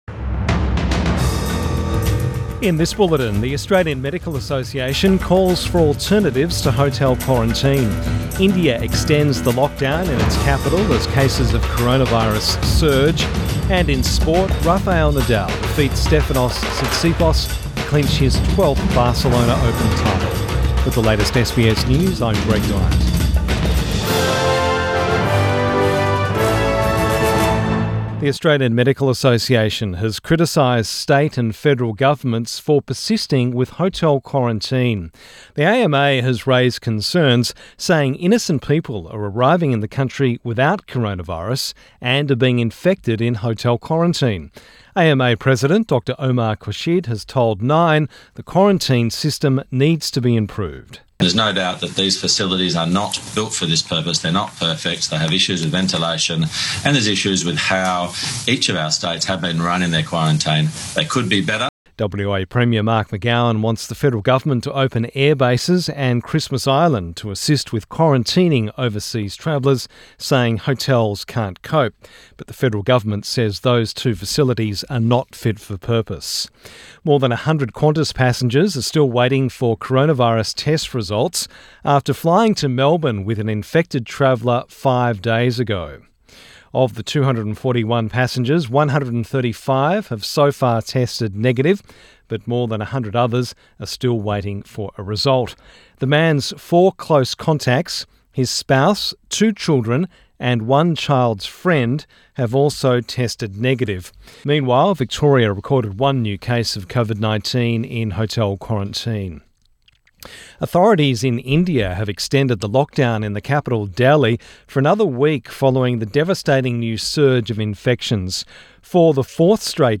Midday bulletin 26 April 2021